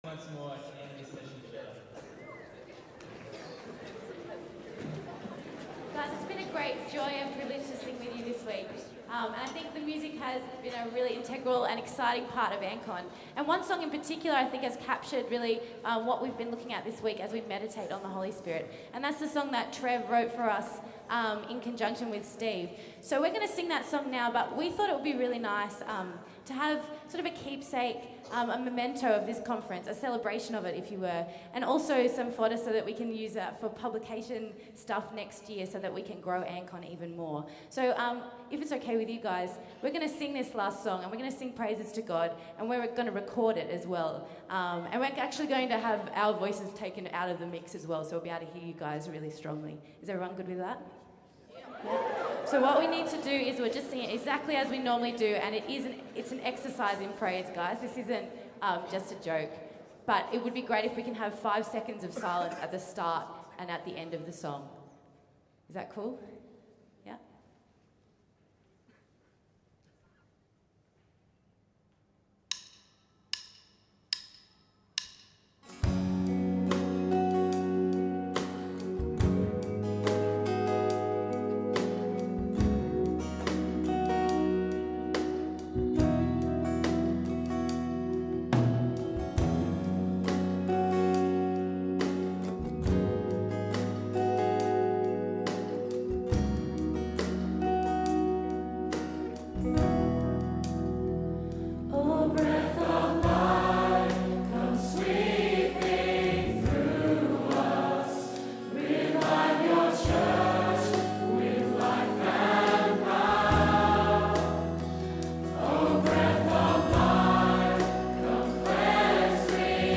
Live Recording: O Breath of Life